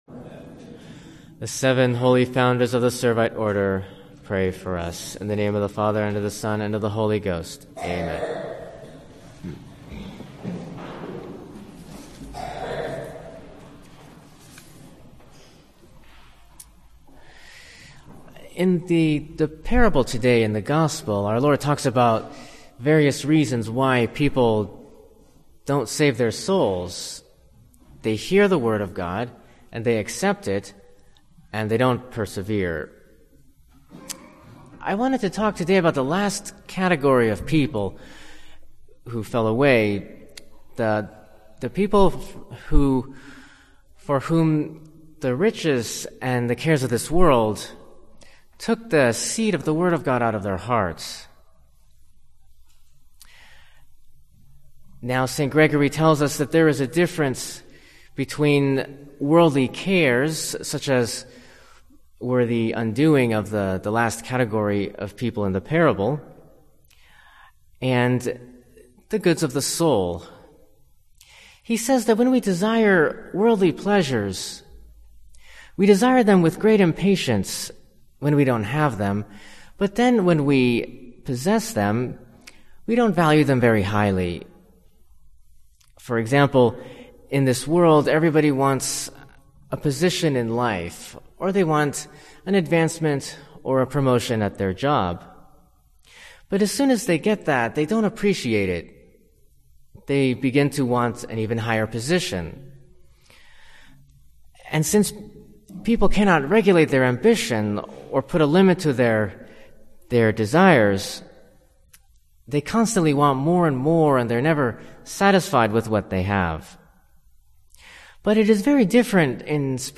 This entry was posted on Sunday, February 12th, 2012 at 3:55 pm and is filed under Sermons.